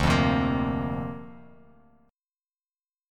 Dm7#5 chord